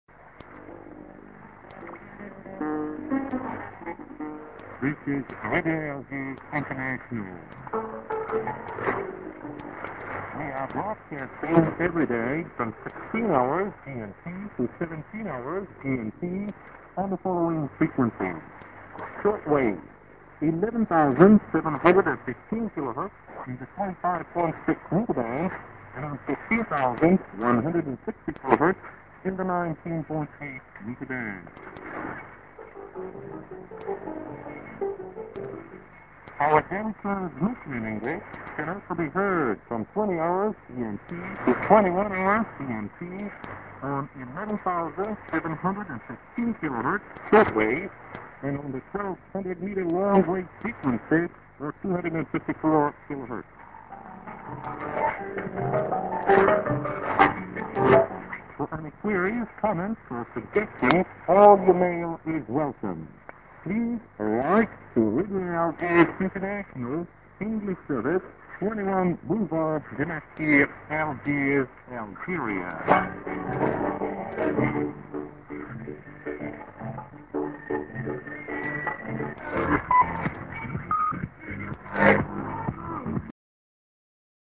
Eng.End ID+SKJ(man)->TS